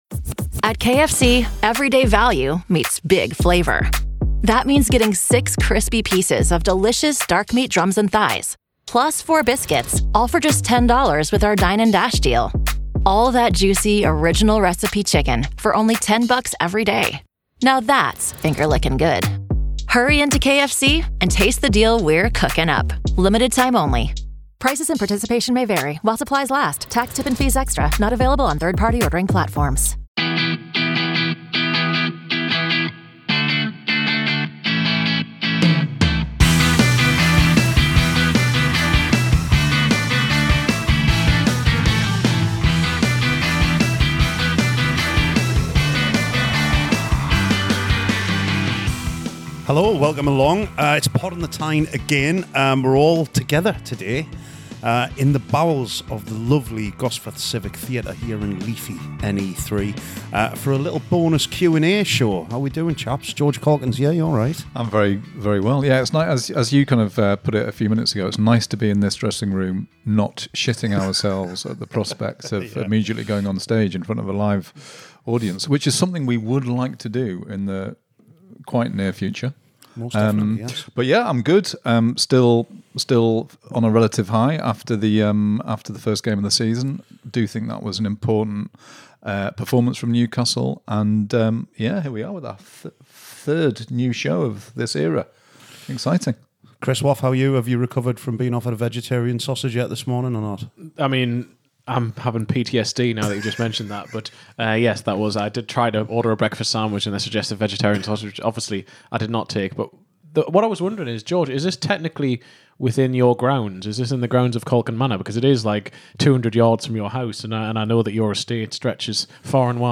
Right, here it is as promised, our post-summer Q&A recorded from the Gosforth Civic Theatre, where we ARE hoping to return to the stage very soon.